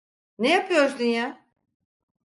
Pronounced as (IPA) /jɑː/